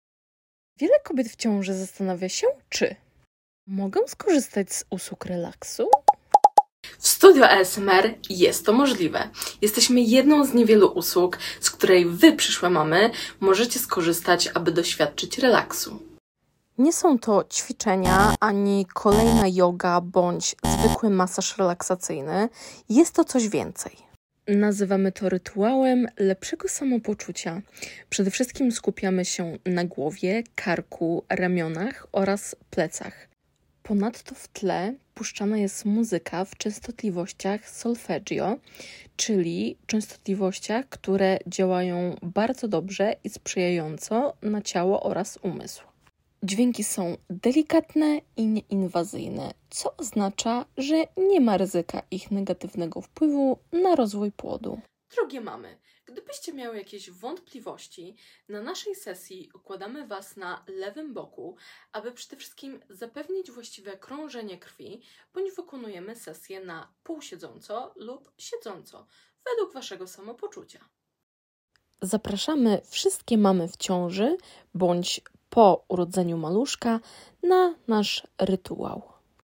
ASMR dla mam🤰💖 To jedna sound effects free download
W moich sesjach skupiam się na delikatnych dźwiękach i kojących bodźcach, które pomagają zredukować stres i napięcie, a także wspierają głębokie połączenie z maluszkiem.